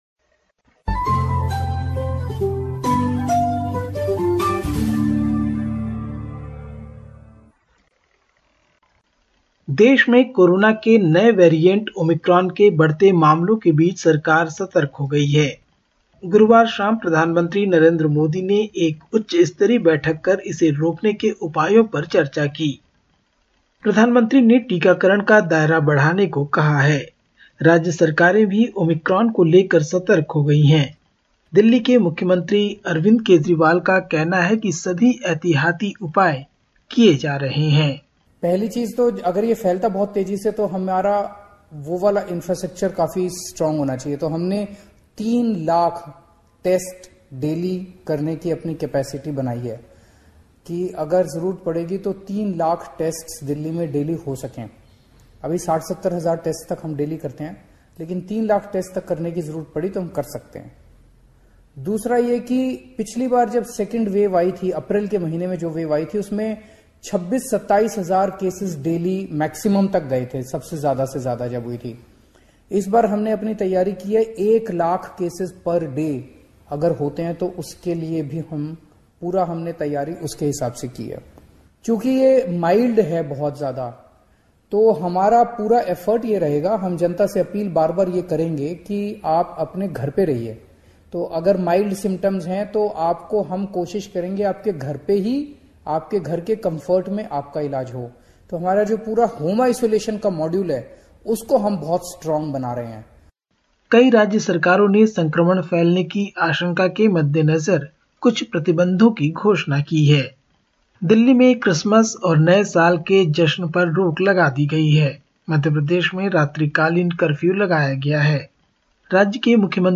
India report: Punjab's Ludhiana court blast kills one, injures five